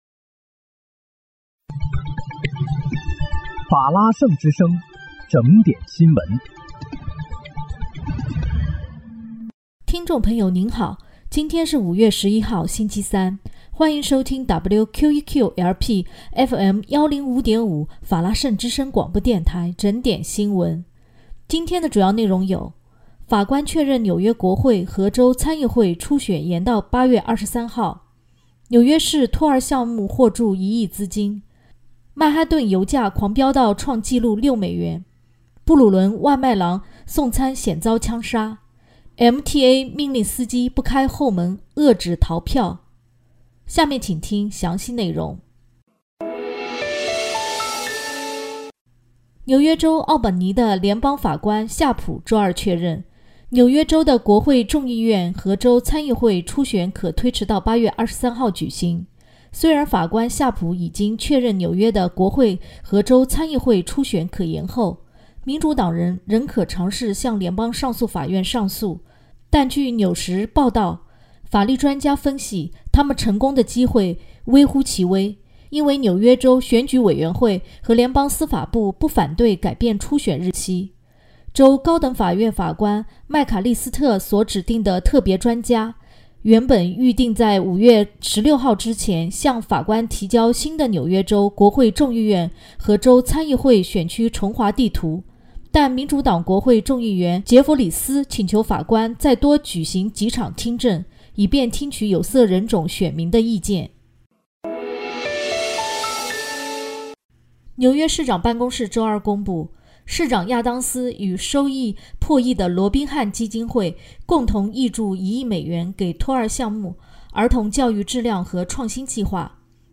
5月11日（星期三）纽约整点新闻